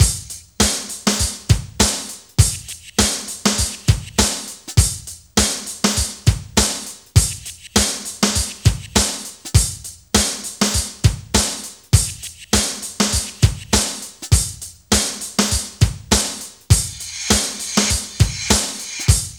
BREAKBEAT4-8.wav